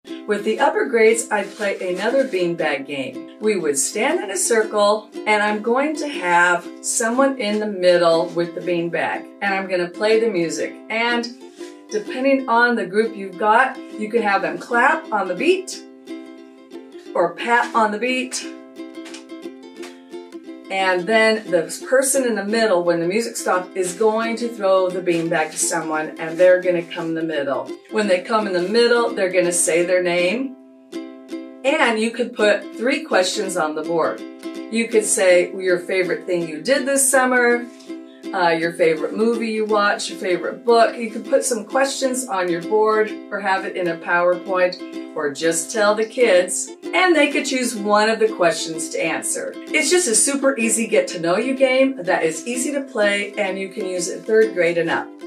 Fun + teamwork in one toss on the first day of music class.